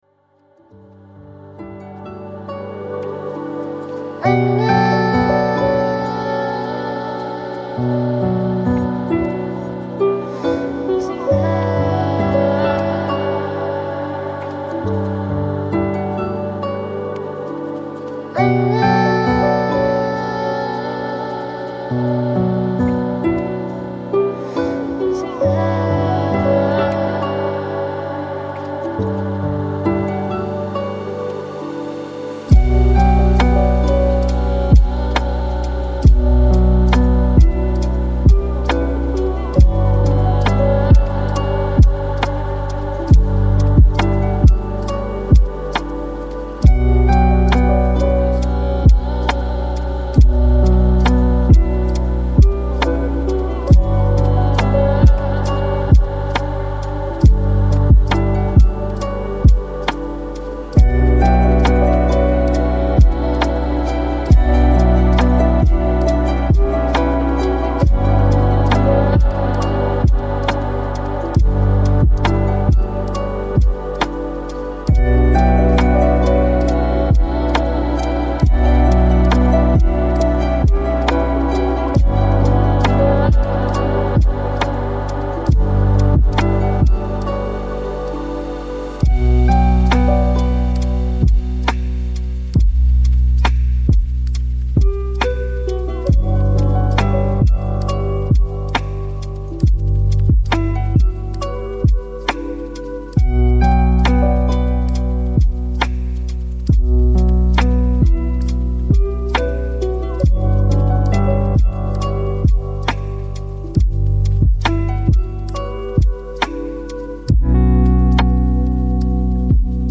Ville Sereine : Concentration Calme